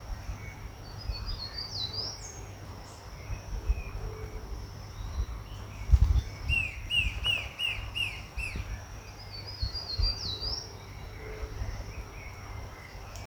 Gritador (Sirystes sibilator)
Nome em Inglês: Sibilant Sirystes
Localidade ou área protegida: Parque Provincial Salto Encantado
Condição: Selvagem
Certeza: Fotografado, Gravado Vocal